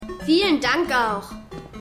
Kenny - Junge                 (Drake Bell)                  ?